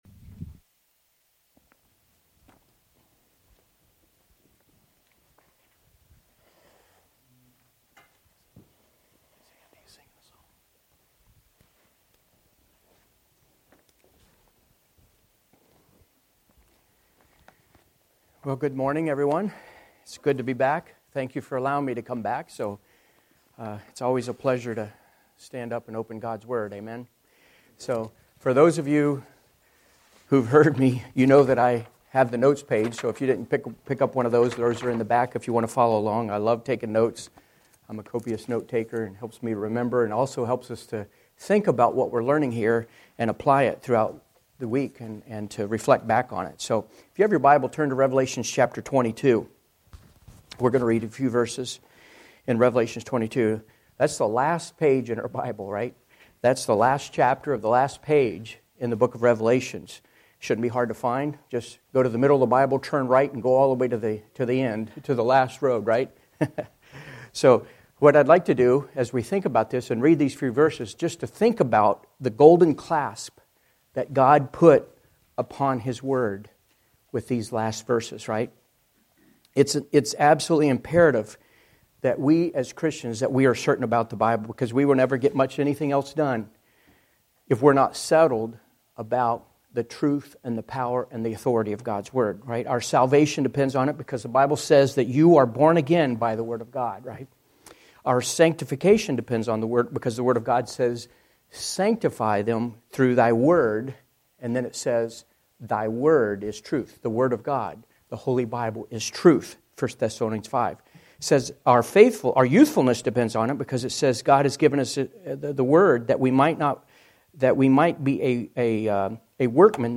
Sermons by First Baptist Church of Elba